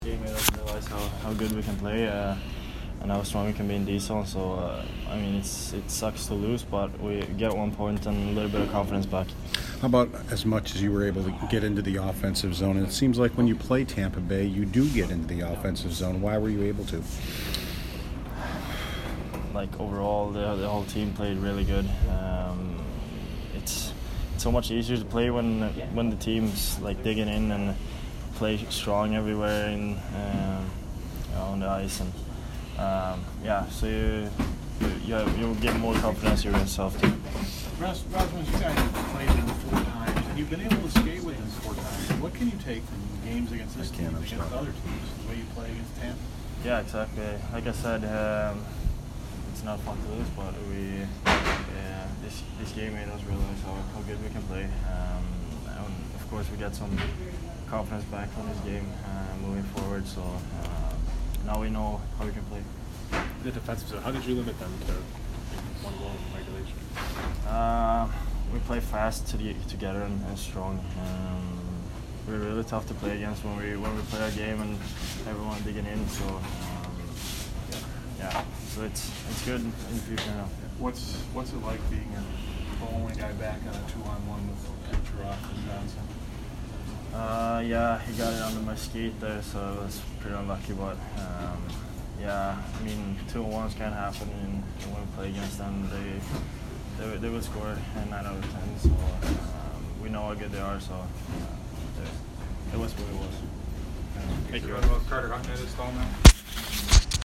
Rasmus Dahlin post-game 2/21